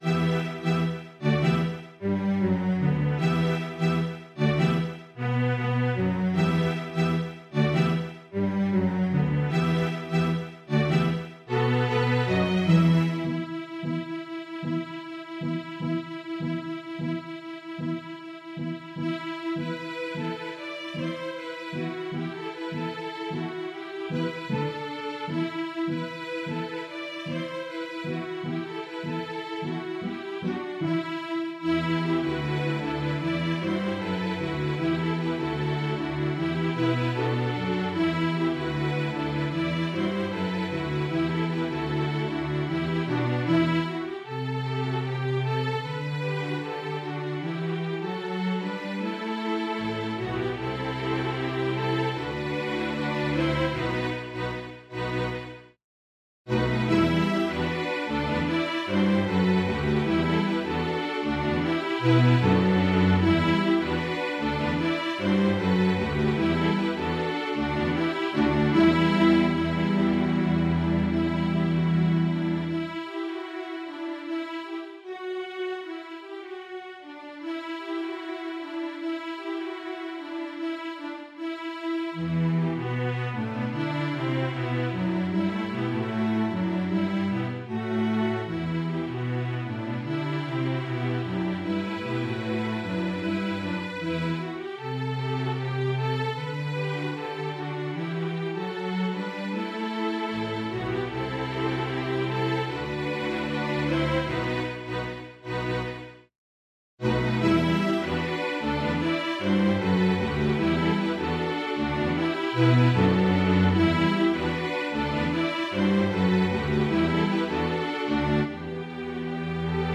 String Orchestra
Energetic and exciting - the students will love this one!